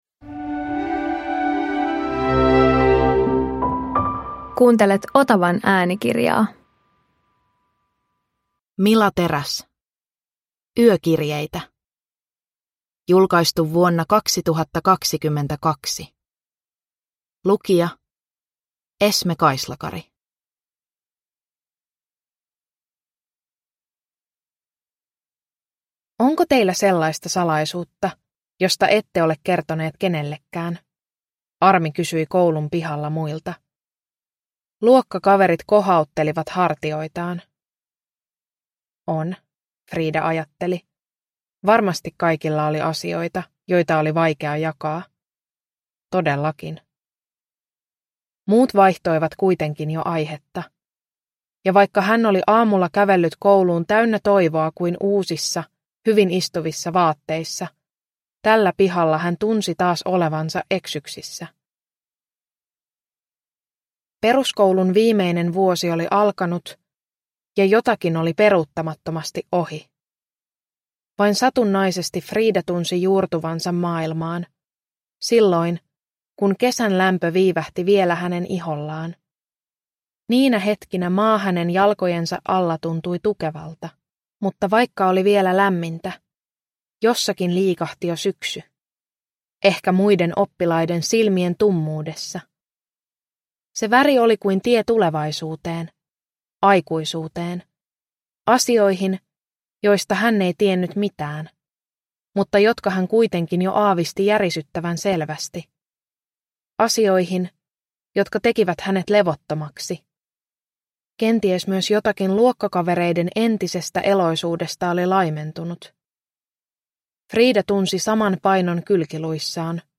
Yökirjeitä – Ljudbok